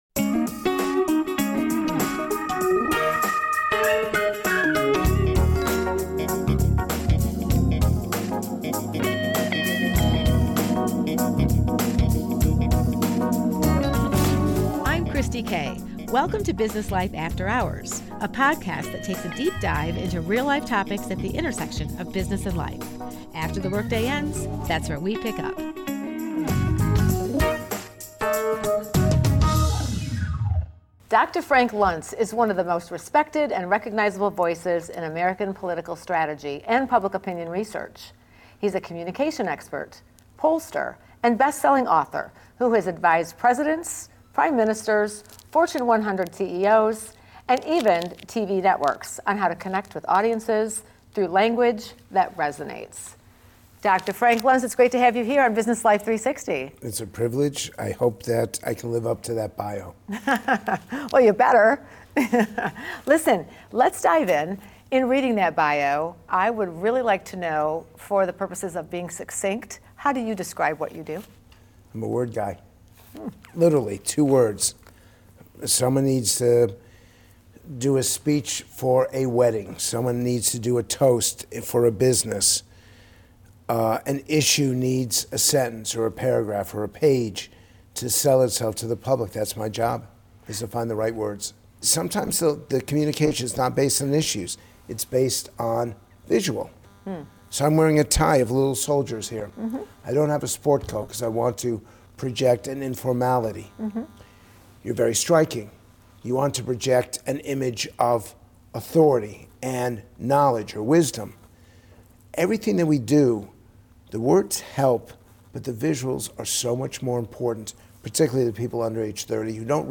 A Conversation with “Word Guy,” Frank Luntz - WGTE Public Media